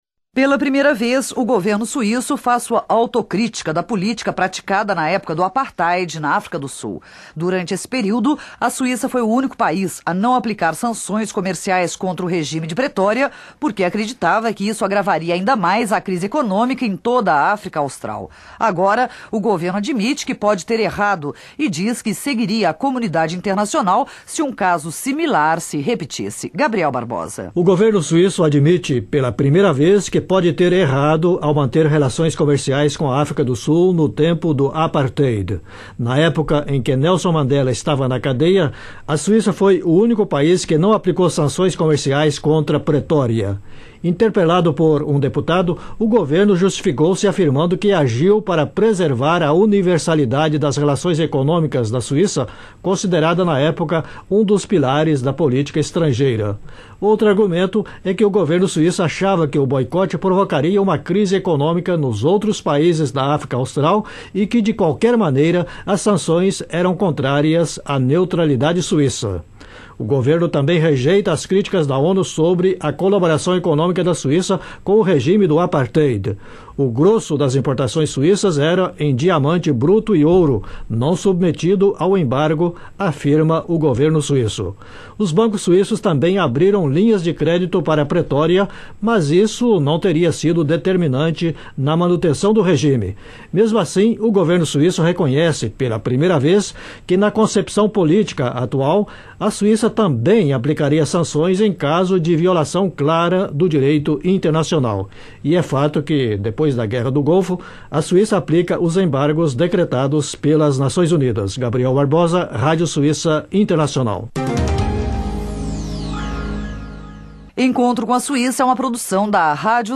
No final dos anos de 1990, o governo suíço fez uma autocrítica de suas relações com a África do Sul no período do apartheid. Reportagem da Rádio Suíça Internacional de novembro de 1997.